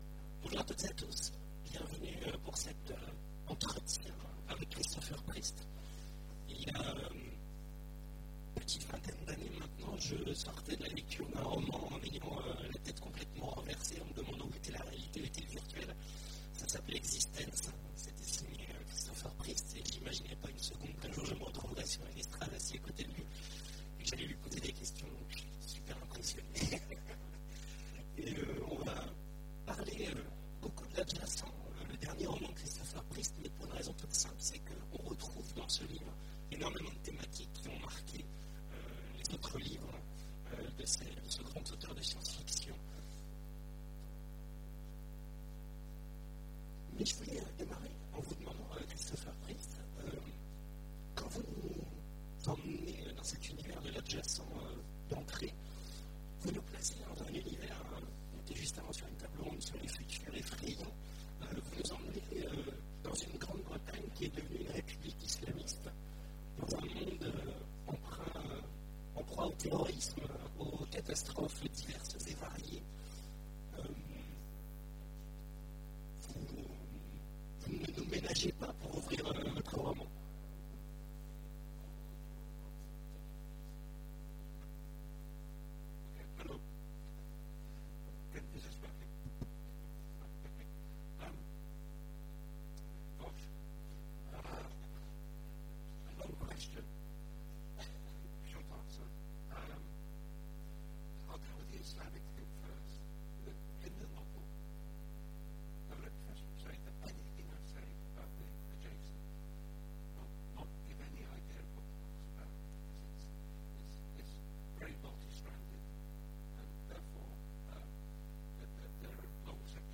Imaginales 2015 : Entretien avec Christopher Priest
- le 31/10/2017 Partager Commenter Imaginales 2015 : Entretien avec Christopher Priest Télécharger le MP3 à lire aussi Christopher Priest Genres / Mots-clés Rencontre avec un auteur Conférence Partager cet article